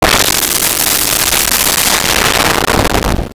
Whale Moan 01
Whale Moan 01.wav